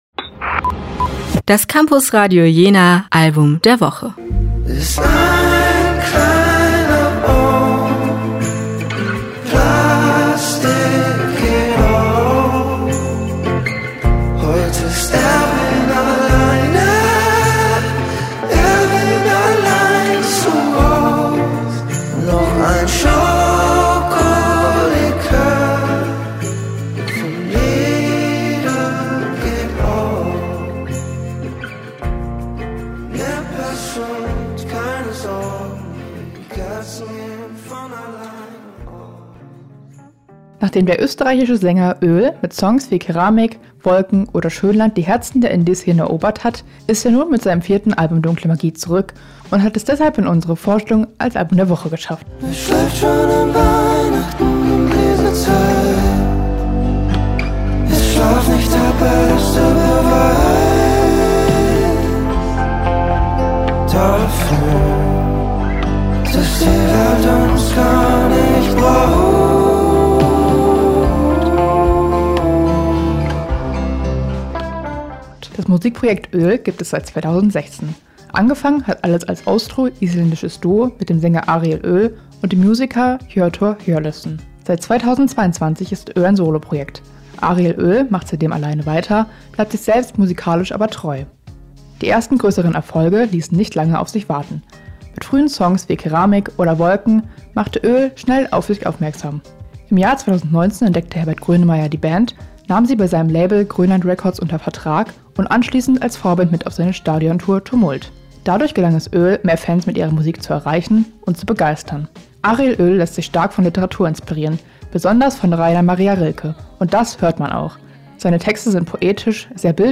der österreichische Sänger